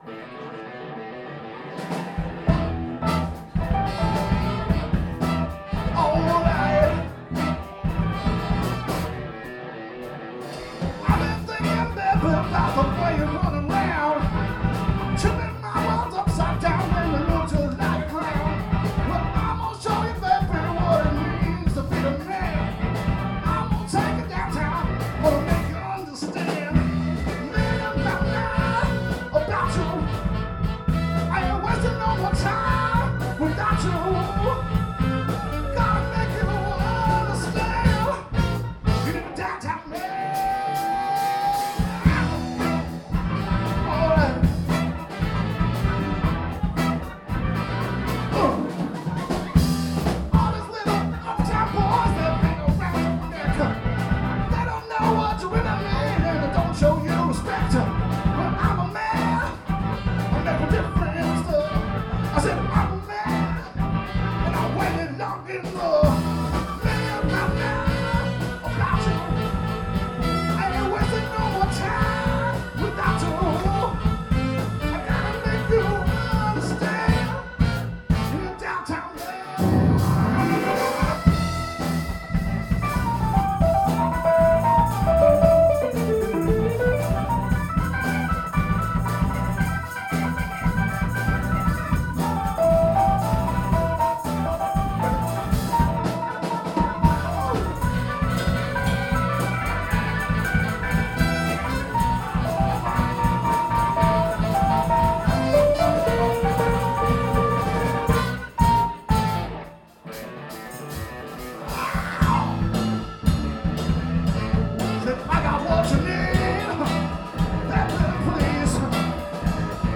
P&G Bar & Grill